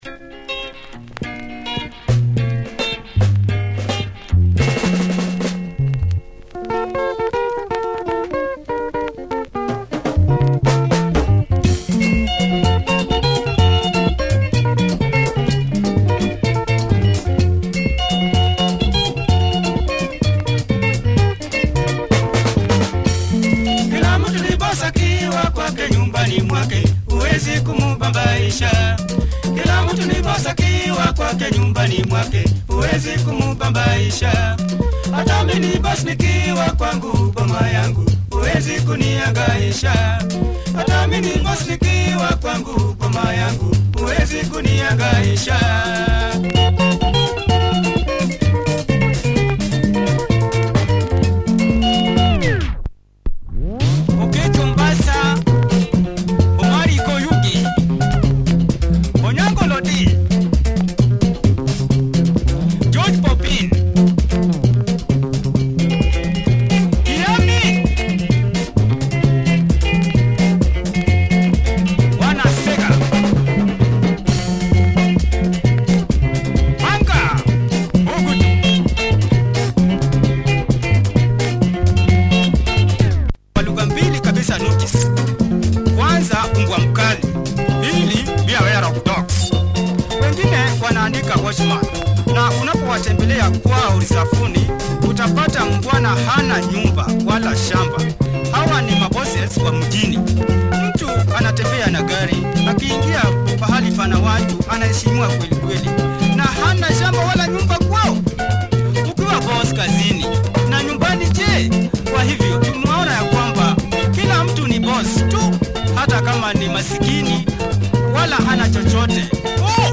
a luo group here presented in Swahili.